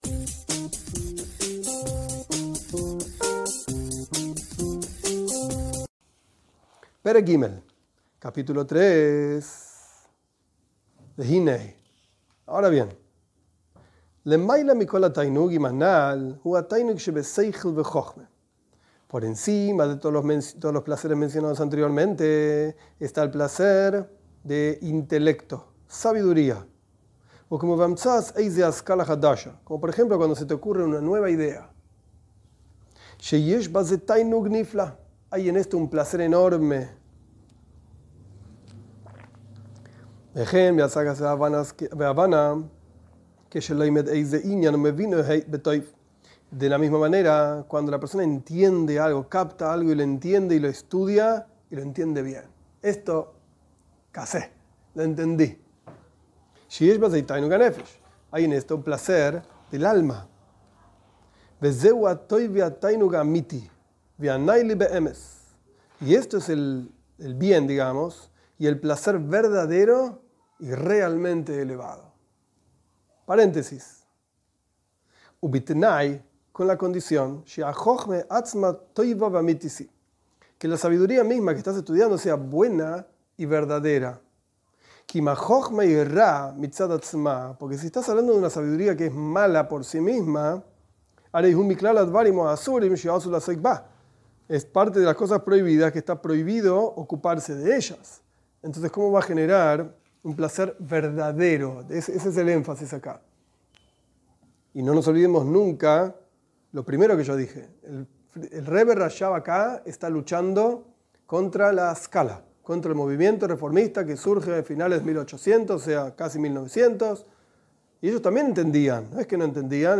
Esta es la primera clase de la serie "Superando impulsos". Se trata de la lectura y explicación del Kuntres uMaaian, del Rebe Rashab, sobre el refinamiento personal. El placer del estudio en general y de la Torá en particular.